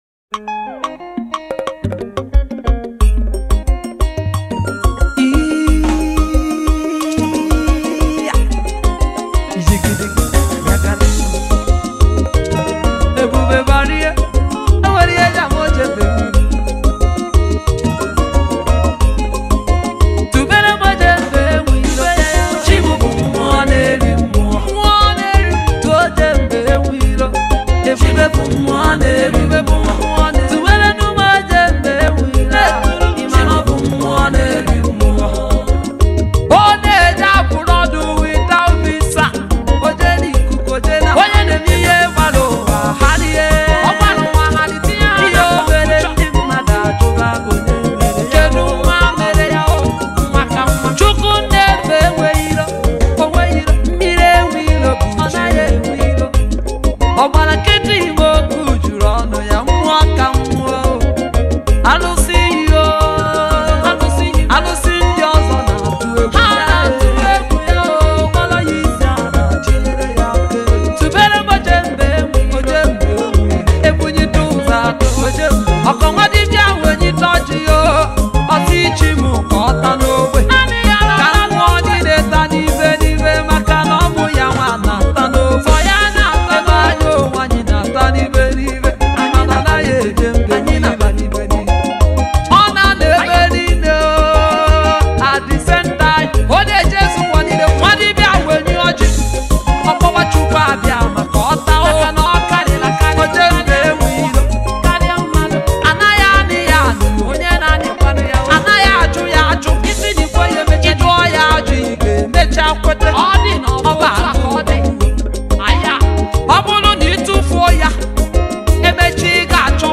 Gospel
has released a Lively worship.